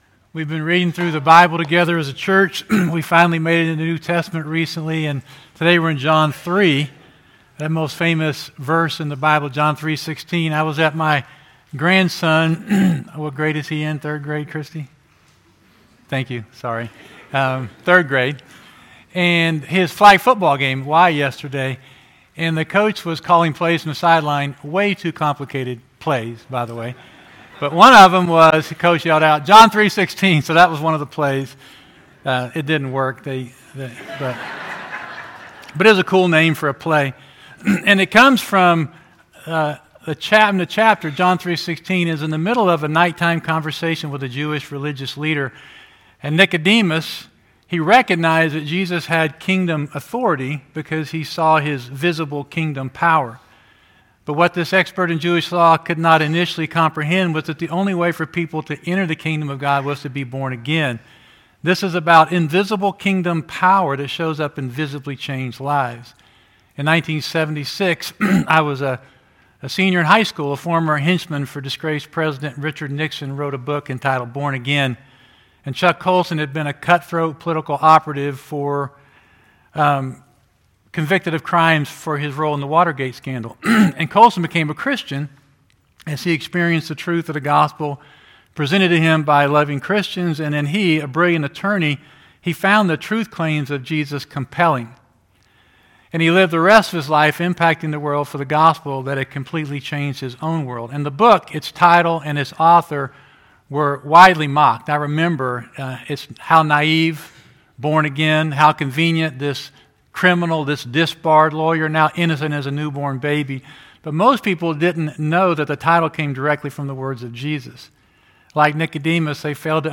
River Community Church Sunday Morning messages